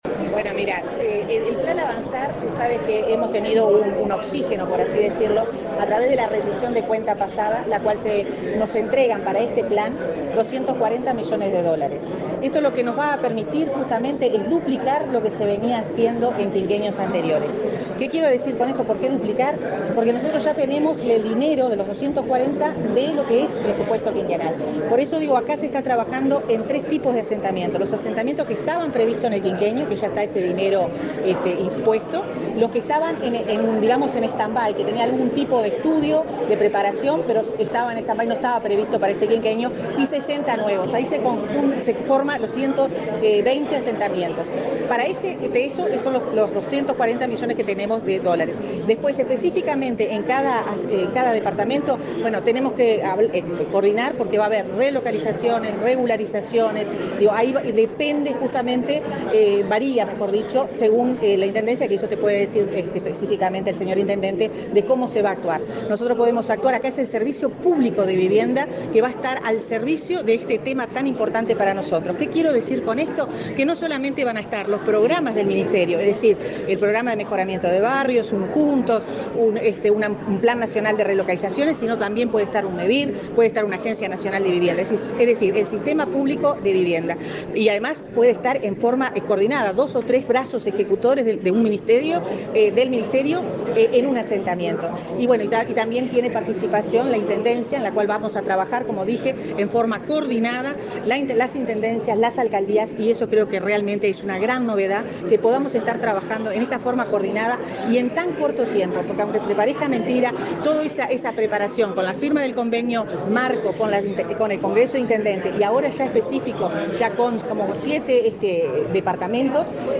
Declaraciones a la prensa de la ministra de Vivienda, Irene Moreira
Declaraciones a la prensa de la ministra de Vivienda, Irene Moreira 07/07/2022 Compartir Facebook X Copiar enlace WhatsApp LinkedIn El intendente interino de Paysandú, Fermín Farinha; la directora nacional de Integración Social y Urbana, Florencia Arbeleche, y la ministra Irene Moreira firmaron un acuerdo para la concreción del plan Avanzar en ese departamento. Luego, Moreira dialogó con la prensa.